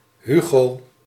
Dutch: [ˈɦyɣoː]